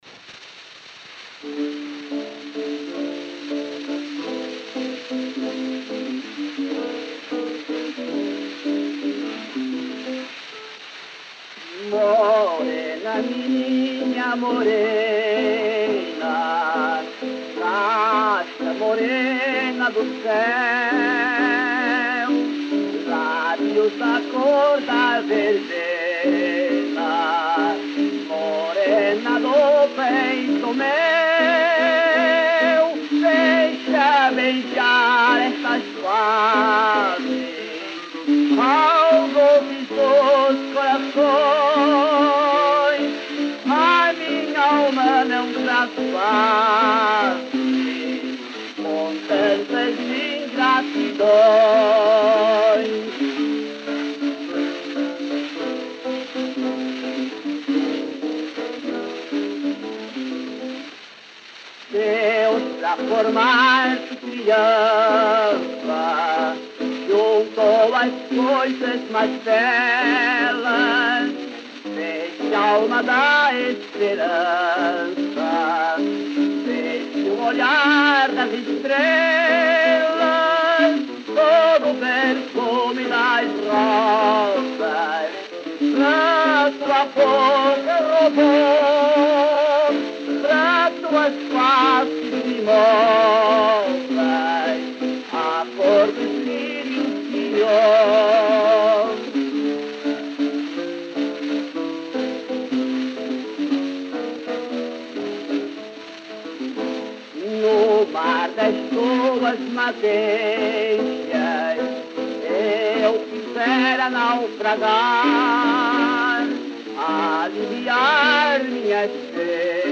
Disco de 78 rotações, também chamado "78 rpm", gravado em apenas um dos lados e com rótulo "rosa".